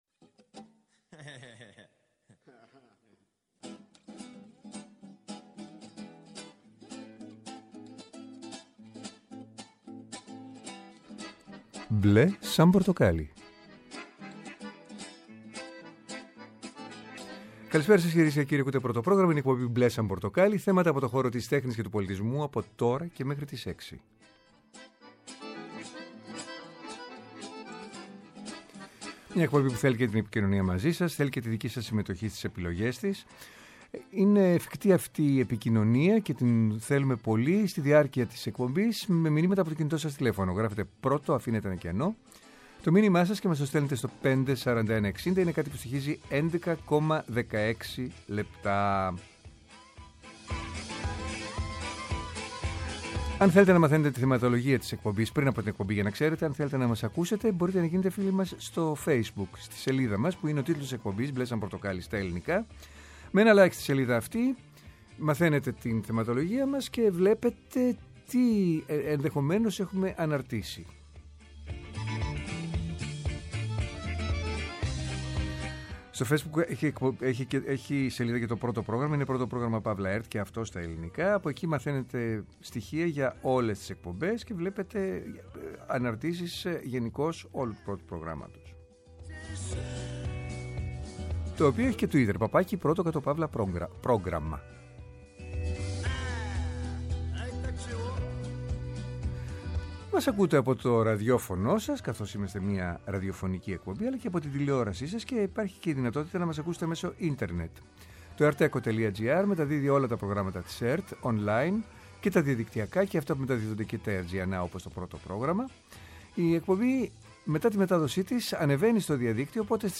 “Μπλε σαν πορτοκάλι”. Θέατρο, κινηματογράφος, μουσική, χορός, εικαστικά, βιβλίο, κόμικς, αρχαιολογία, φιλοσοφία, αισθητική και ό,τι άλλο μπορεί να είναι τέχνη και πολιτισμός, καθημερινά από Δευτέρα έως Πέμπτη 5-6 το απόγευμα από το Πρώτο Πρόγραμμα. Μια εκπομπή με εκλεκτούς καλεσμένους, άποψη και επαφή με την επικαιρότητα.